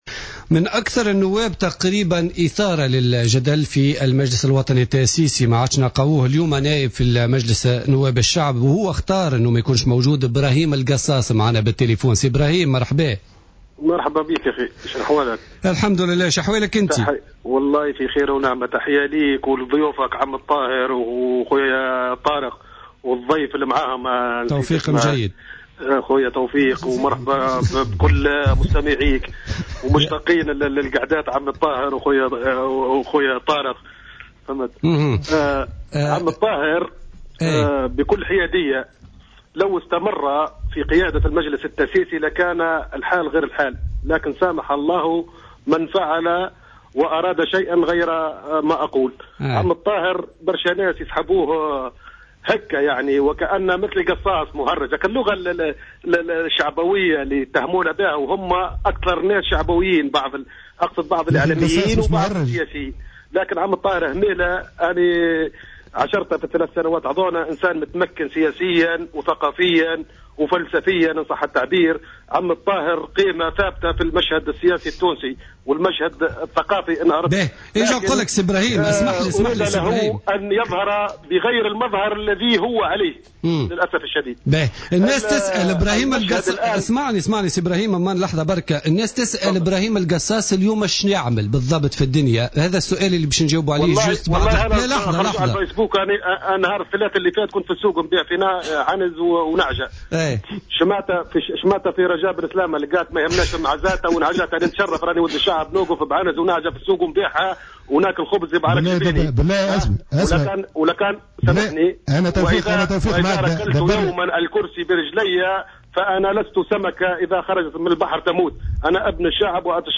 L’ancien député à l’assemblée nationale constituante, Brahim Gassas, est intervenu sur les ondes de Jawhara FM dans le cadre de l’émission Politica du jeudi 4 décembre 2014.